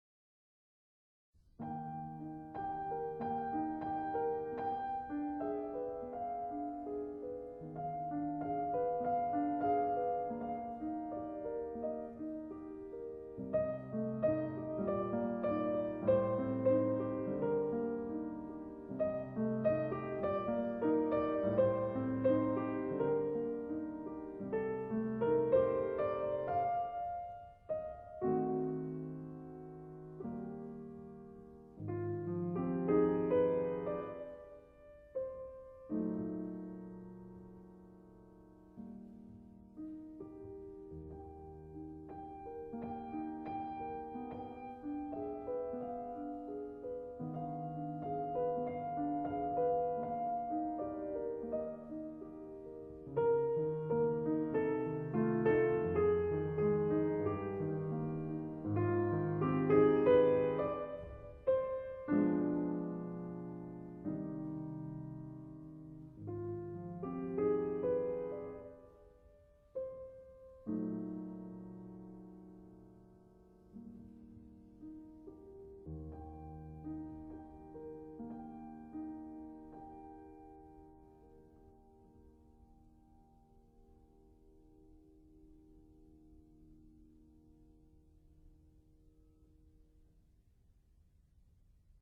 Cours complémentaire accessible dès Q1 piano